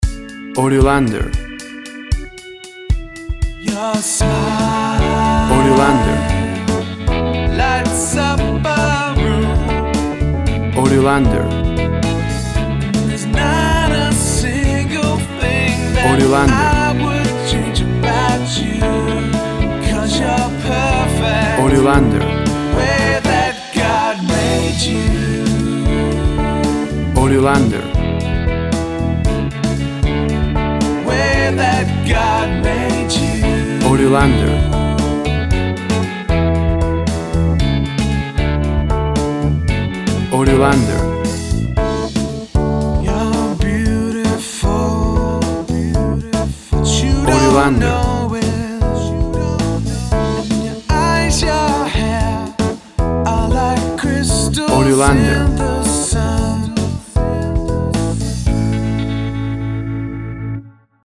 WAV Sample Rate 16-Bit Stereo, 44.1 kHz
Tempo (BPM) 115